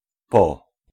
Ääntäminen
IPA : [laik]
IPA : /laɪk/